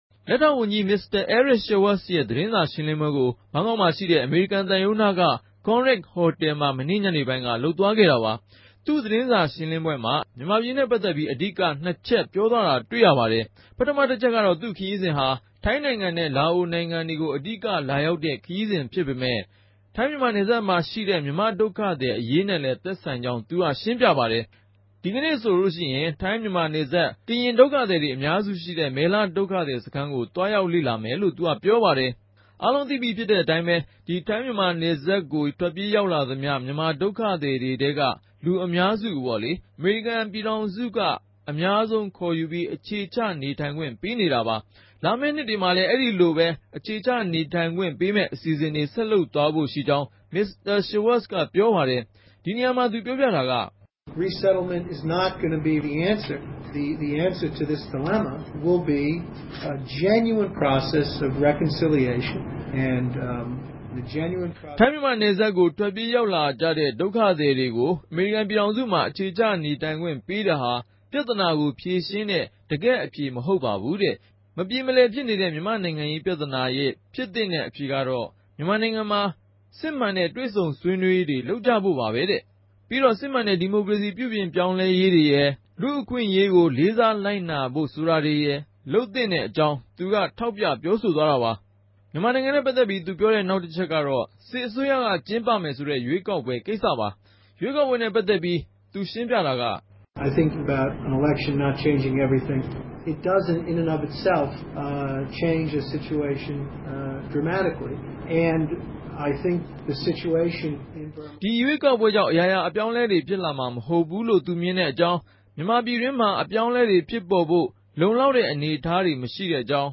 သတင်းပေးပိုႛခဵက်